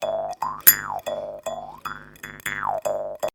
Jawharp Pluck
Jawharp Pluck is a free music sound effect available for download in MP3 format.
yt_OHnd0OrOA5M_jawharp_pluck.mp3